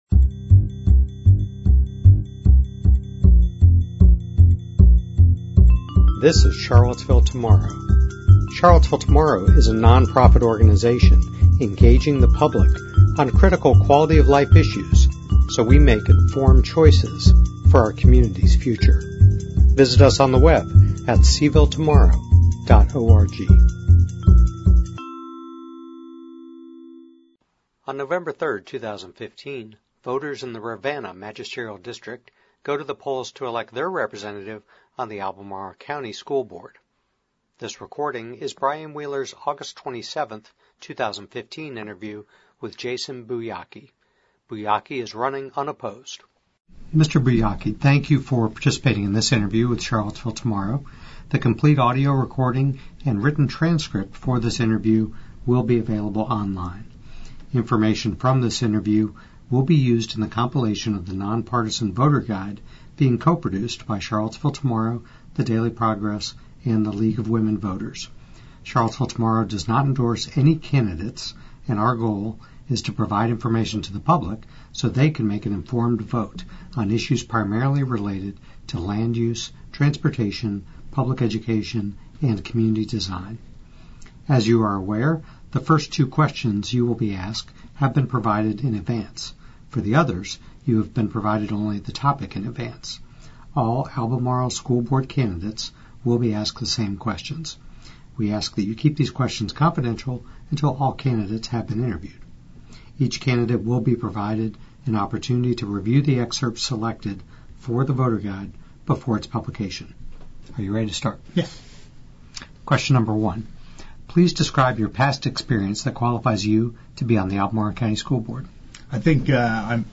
Candidate interview audio